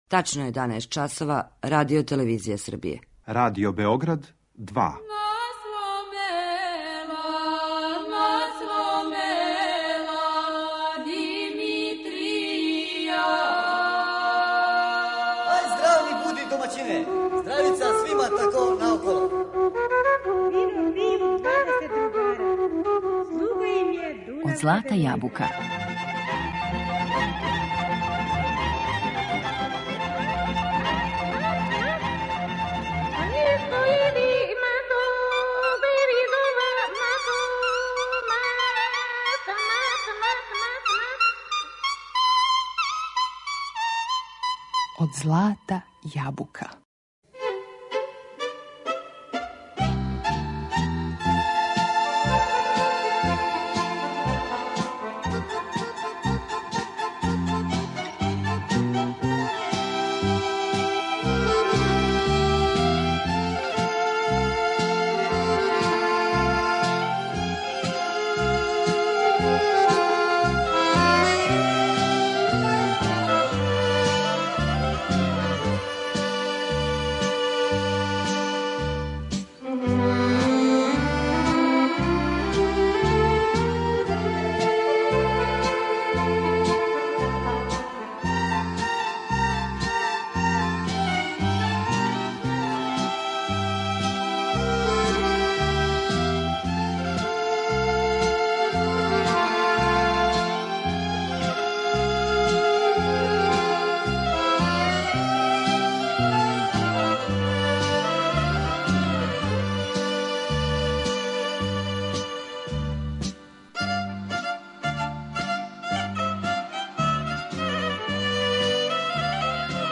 виолиниста